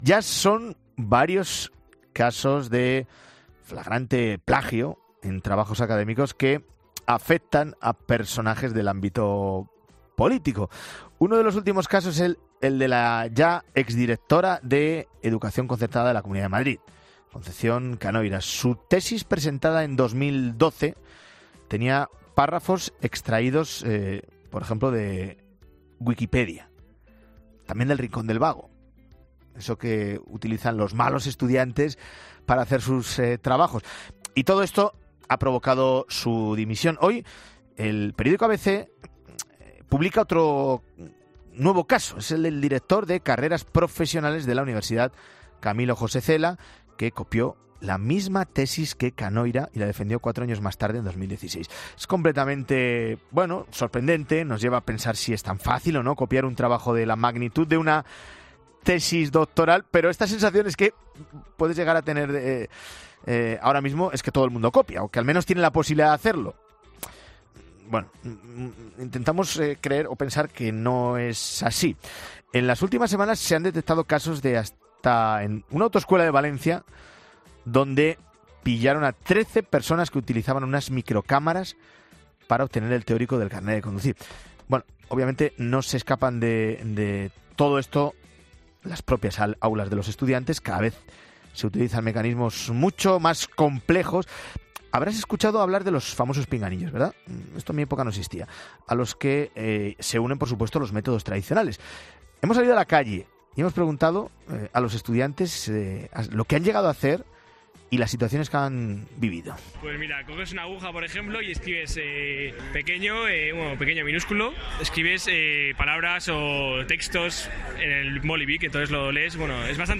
Hemos estado en la calle para preguntarles a ellos que es lo que han llegado a hacer y que situaciones han presenciado.